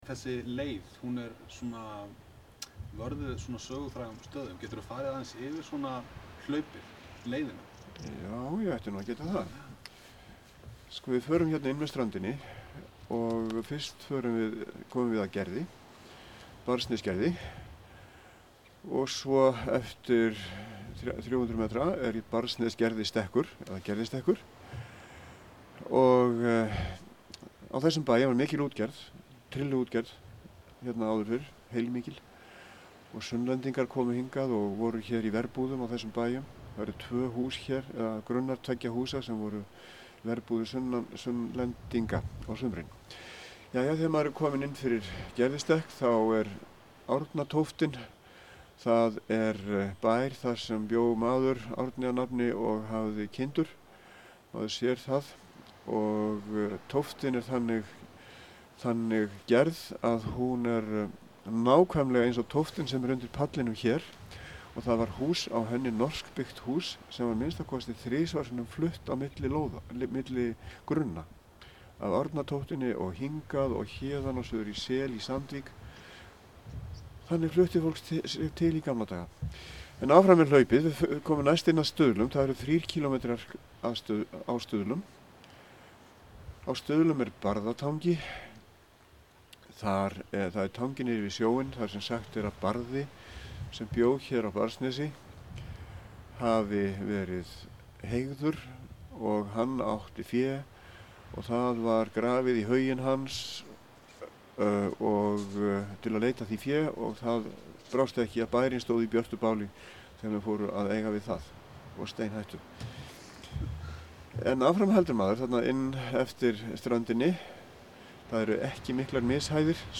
Viðtal tekið úti í Barðsnesi föstudaginn 3. ágúst 2007 vegna gerðar heimildarmyndar um hlaupið.